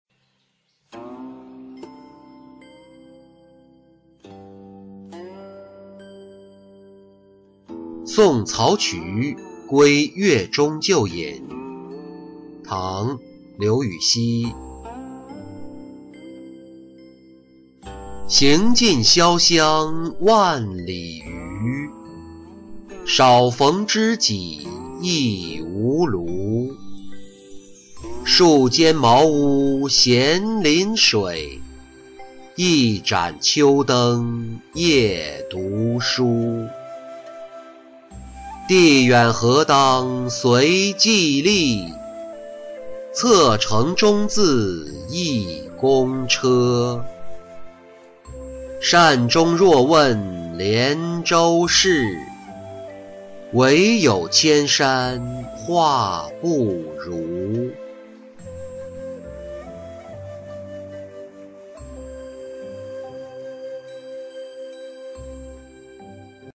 送曹璩归越中旧隐诗-音频朗读